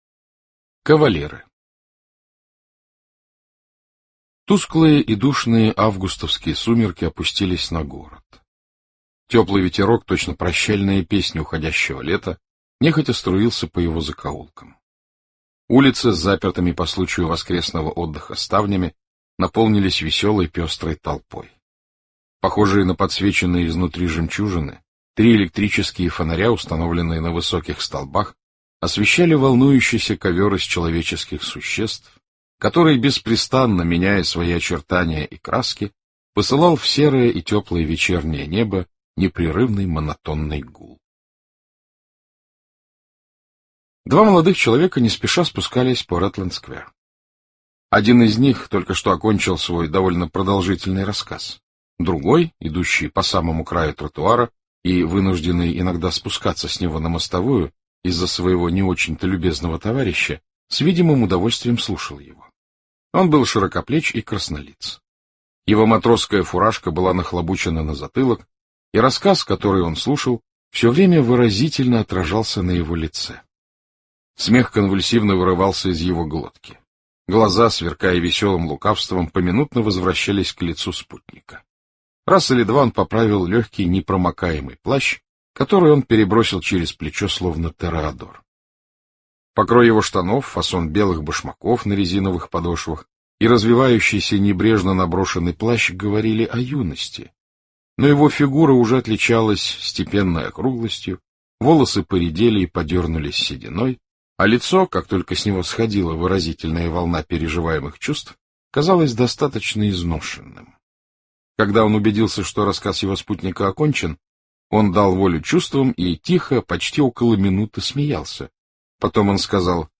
Кавалеры Джойс аудио книгу слушать, Кавалеры Джойс аудио книгу слушать бесплатно